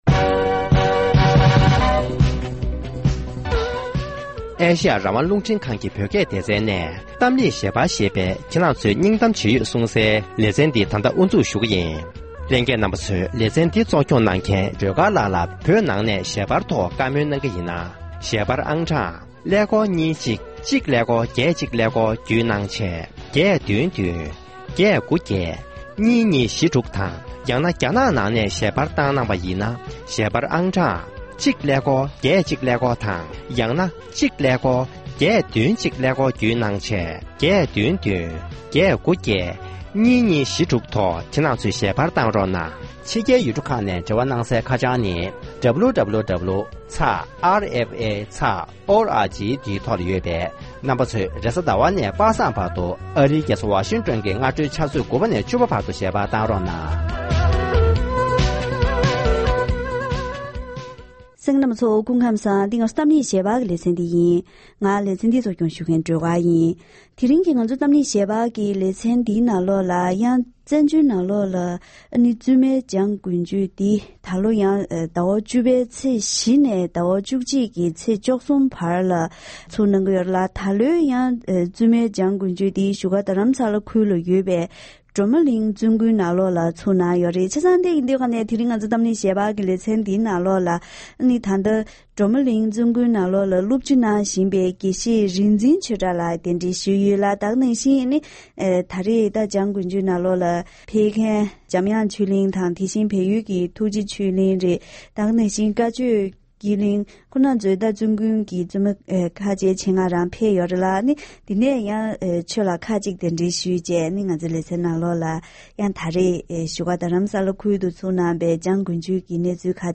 ༄༅༎དེ་རིང་གི་གཏམ་གླེང་ཞལ་པར་ལེ་ཚན་ནང་སྤྱི་ཟླ་༡༠ཚེས་༤ཉིན་ནས་བཞུགས་སྒར་རྡ་རམ་ས་ལ་ཁུལ་དུ་ཡོད་པའི་སྒྲོལ་མ་གླིང་བཙུན་དགོན་དུ་ཟླ་བ་གཅིག་རིང་བཙུན་མའི་འཇང་དགུན་ཆོས་ཐེངས་༡༩འདི་ཚུགས་ཡོད་པས། འཇང་དགུན་ཆོས་ནང་མཉམ་ཞུགས་གནང་མཁན་བཙུན་མ་དང་འབྲེལ་ཡོད་ལ་བཀའ་མོལ་ཞུས་པ་ཞིག་གསན་རོགས་གནང་།